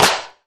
• Disco Hand Clap Sound D Key 25.wav
Royality free hand clap sound - kick tuned to the D note. Loudest frequency: 2671Hz
disco-hand-clap-sound-d-key-25-opv.wav